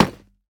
Minecraft Version Minecraft Version 25w18a Latest Release | Latest Snapshot 25w18a / assets / minecraft / sounds / block / nether_bricks / break2.ogg Compare With Compare With Latest Release | Latest Snapshot